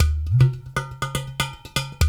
120 -UDU 0AR.wav